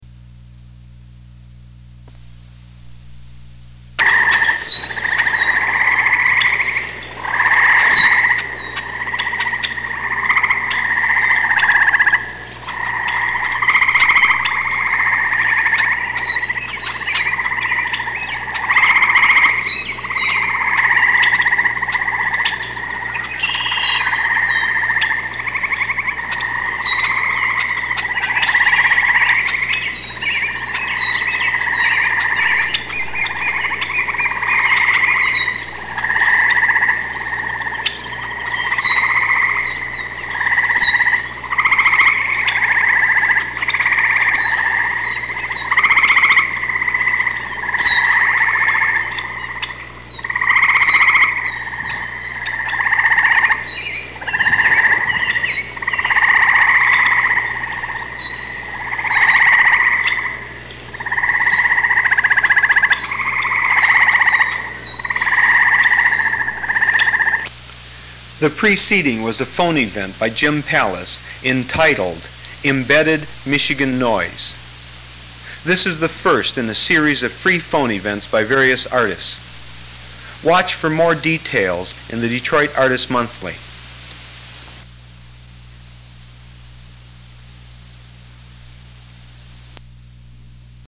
Spring sounds of mating frogs and red wing blackbirds recorded in a Michigan swamp.